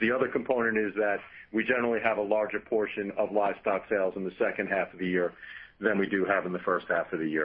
neutral.mp3